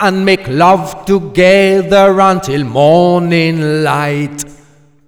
OLDRAGGA6 -R.wav